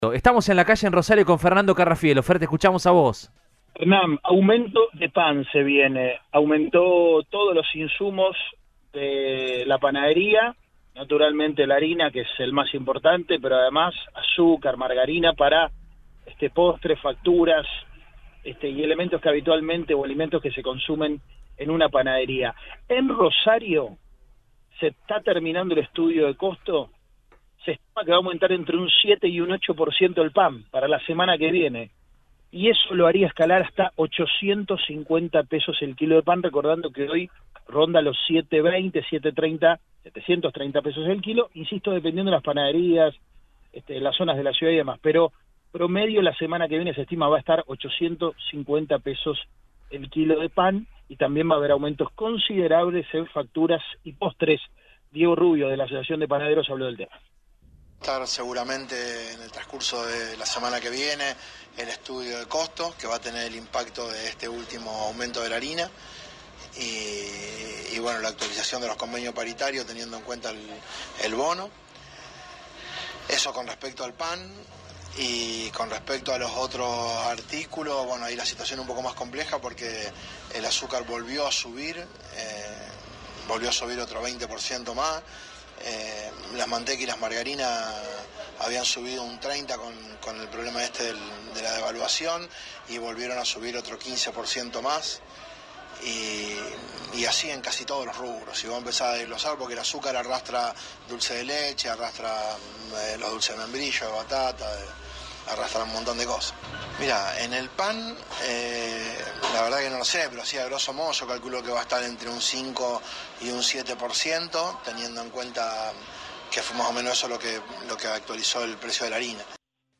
habló con el móvil de Cadena 3 Rosario, en Radioinforme 3